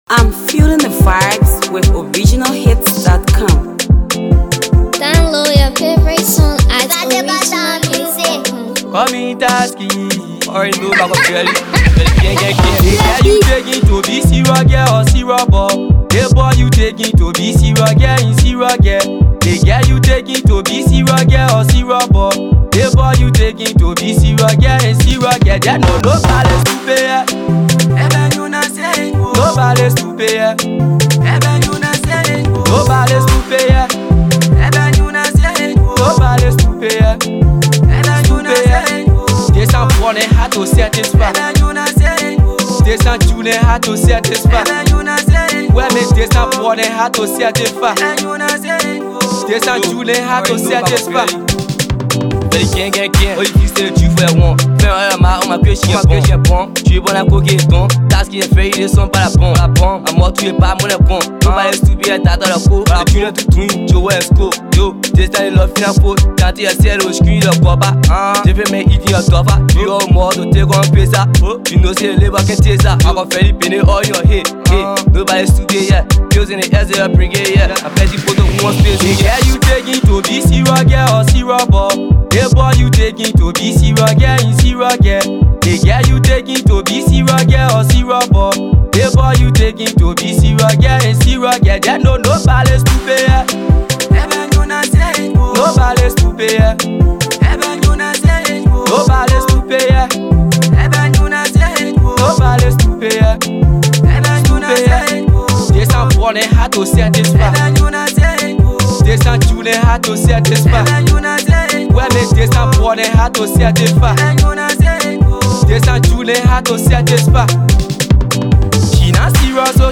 Dope banger